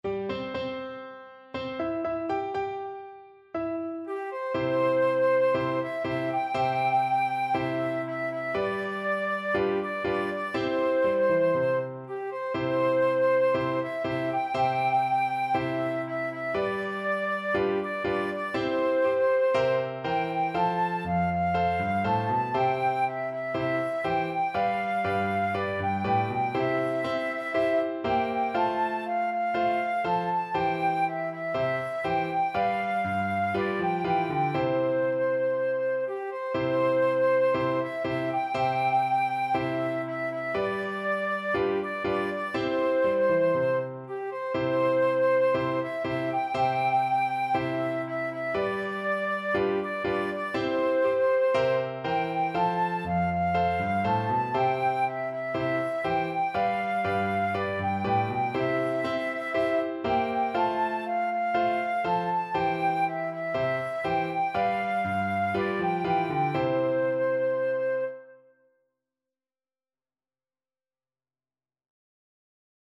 4/4 (View more 4/4 Music)
March =c.120
Flute  (View more Easy Flute Music)
Traditional (View more Traditional Flute Music)